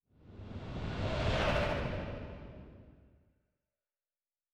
pgs/Assets/Audio/Sci-Fi Sounds/Movement/Distant Ship Pass By 6_2.wav at master
Distant Ship Pass By 6_2.wav